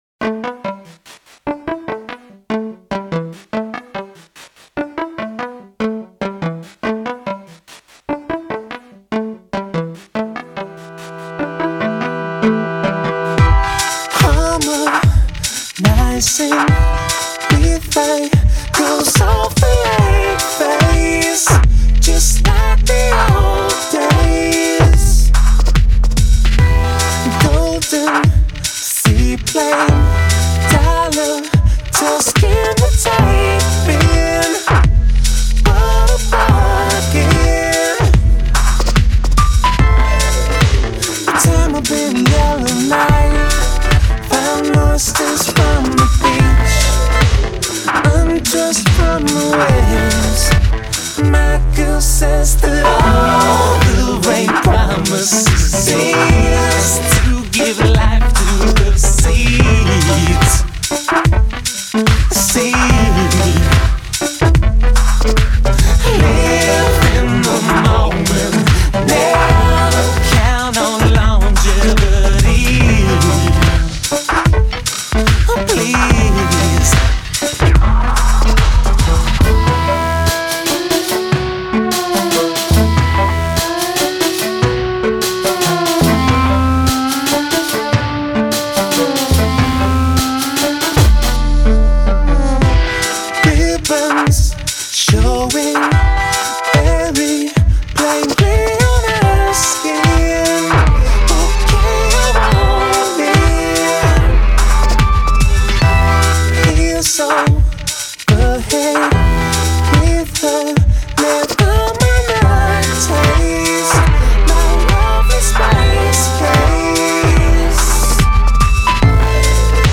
Today’s Indie